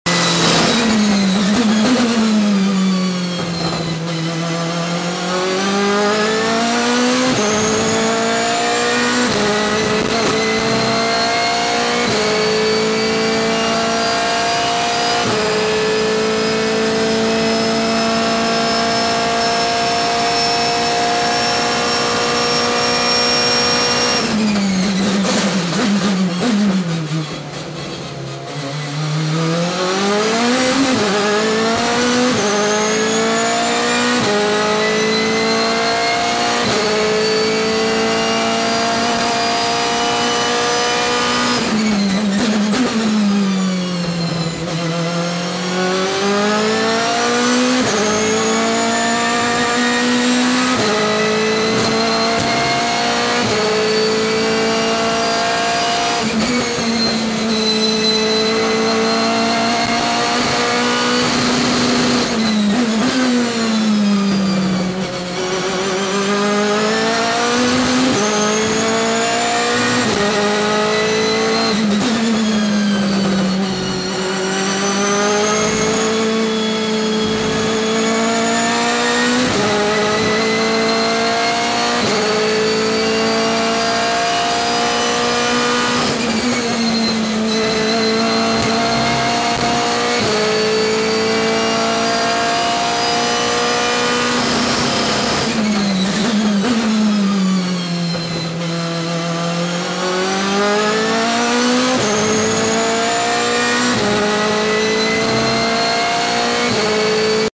V8_Hockenheimring.mp3